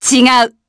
Demia-Vox-Deny_jp.wav